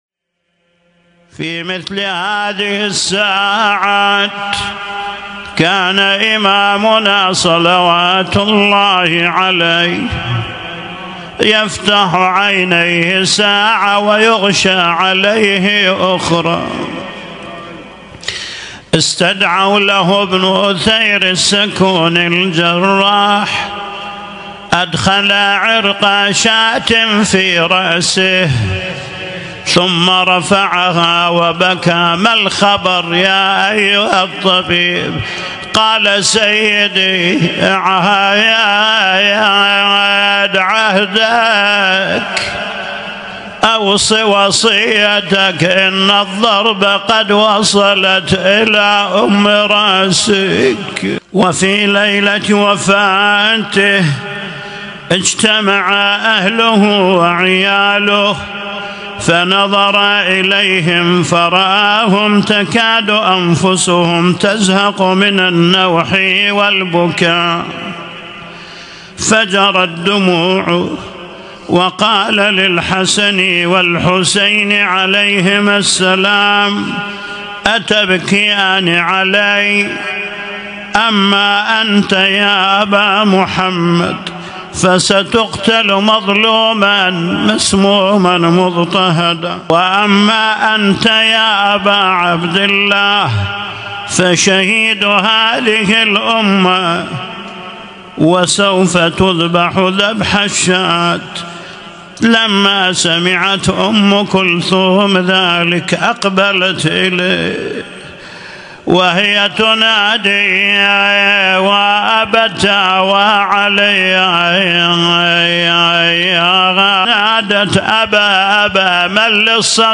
اسم التصنيف: المـكتبة الصــوتيه >> الصوتيات المتنوعة >> النواعي